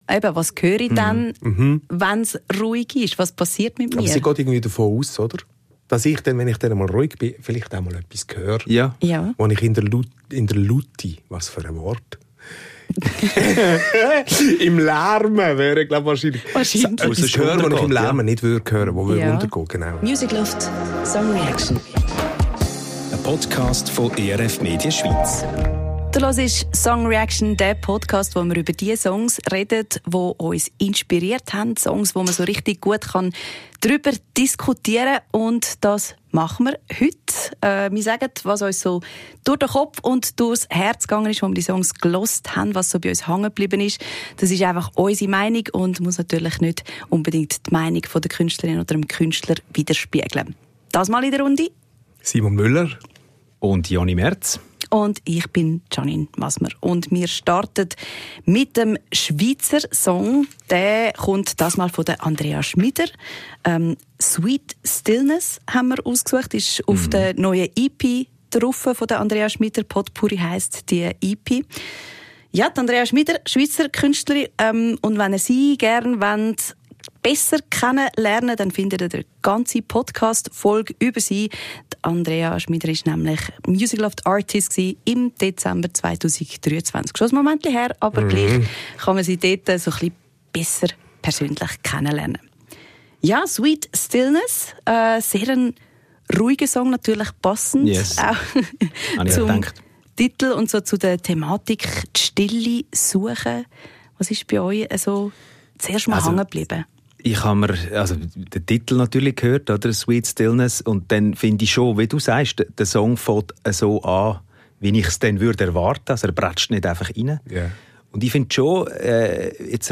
Drei Hosts, drei Songs – und ganz viele Gedanken.